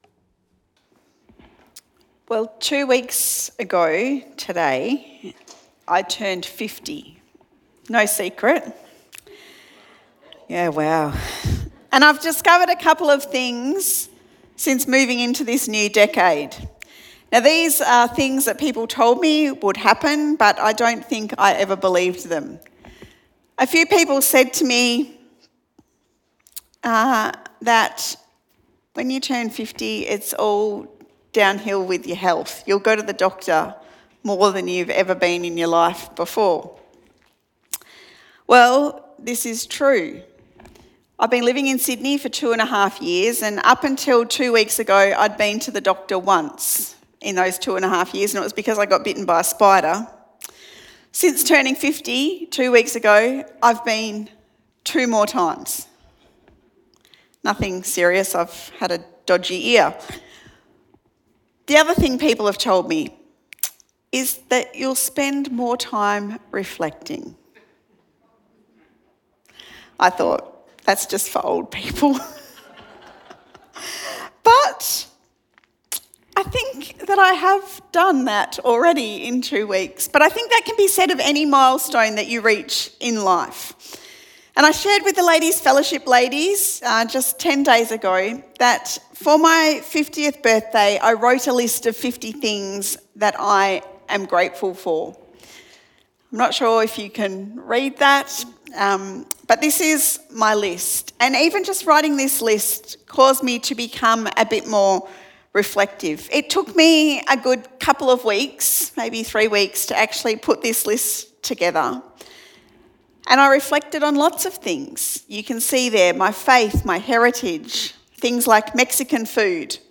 Sermon Podcasts Foundations